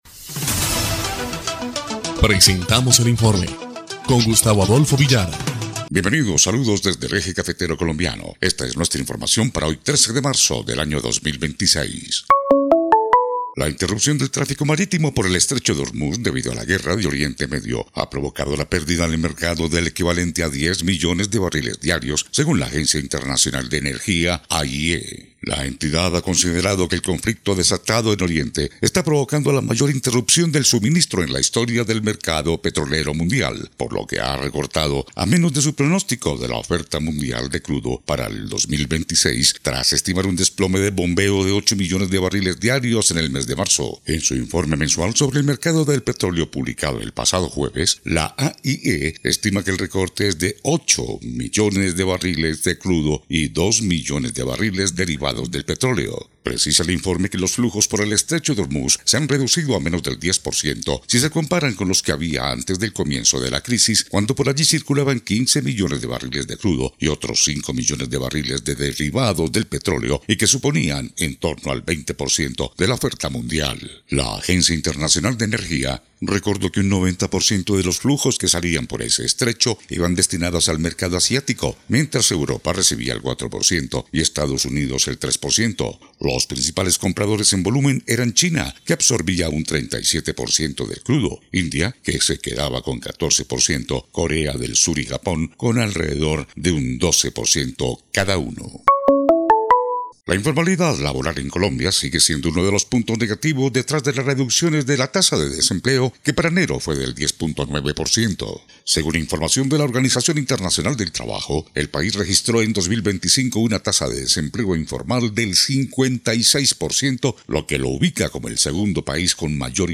EL INFORME 2° Clip de Noticias del 13 de marzo de 2026